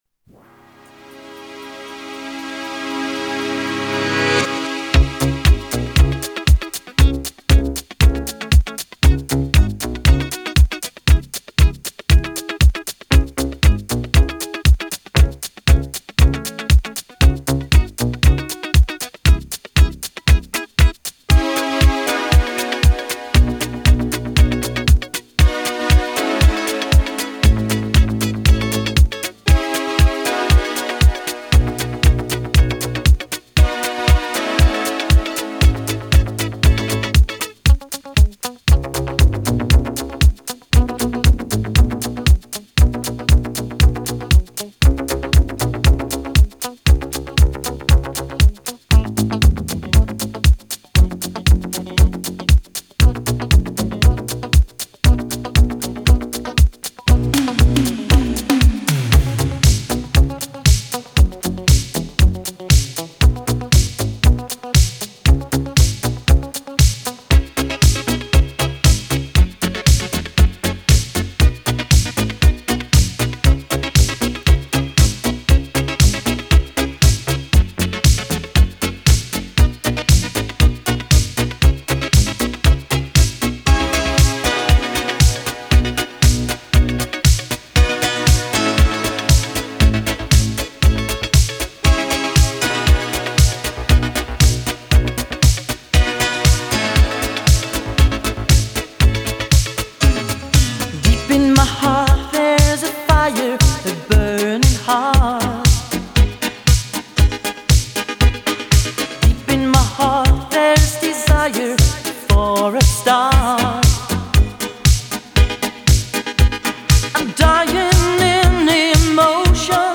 Synth-Pop, Eurodisco